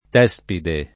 t$Spide]: la Tragedia ritrovata da Tespide [la traJ$dLa ritrov#ta da tt$Spide] (Salvini) — solo la prima forma nel nome del carro di Tespi, il teatro ambulante di quell’antico drammaturgo (sec. VI a. C.), o una sua imitaz. moderna